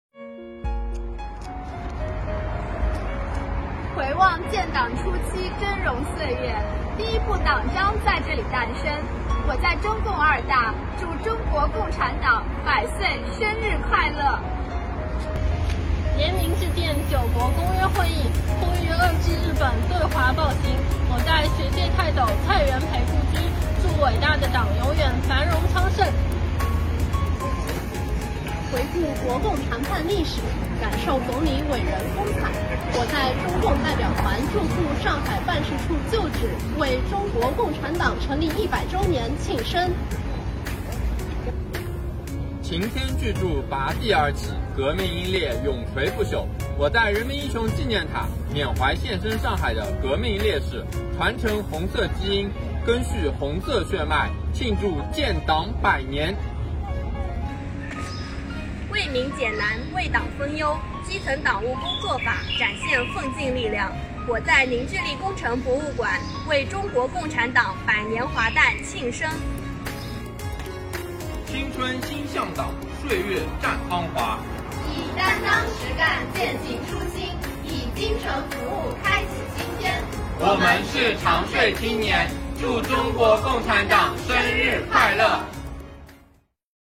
上海市长宁区税务局青年理论学习小组的税务青年们用双脚丈量红色沃土，在中共二大会址、蔡元培故居、周公馆、人民英雄纪念塔和凝聚力工程博物馆回望建党百年峥嵘岁月，在红色寻访中感知、理解、传承红色基因，以担当实干践行初心，以精诚服务开启新篇，共祝中国共产党生日快乐！